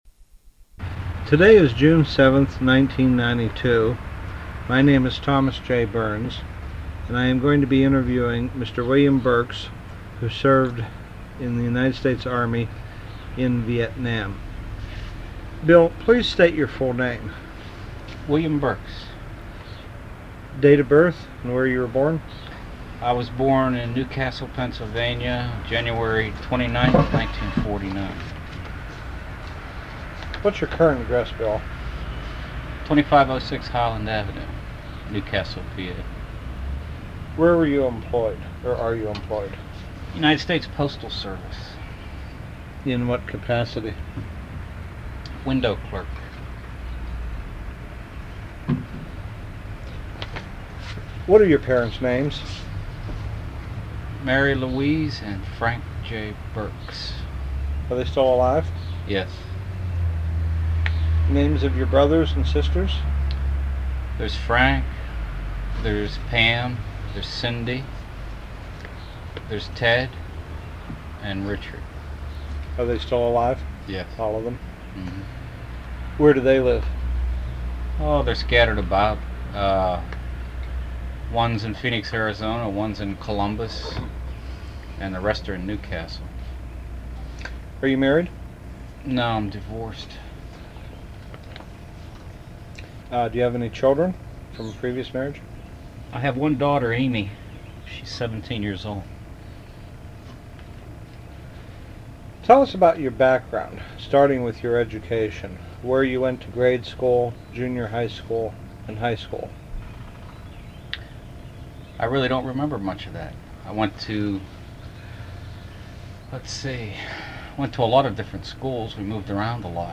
Transcript of interview taped on June 7, 1992.